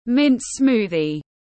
Sinh tố bạc hà tiếng anh gọi là mint smoothie, phiên âm tiếng anh đọc là /mɪnt ˈsmuː.ði/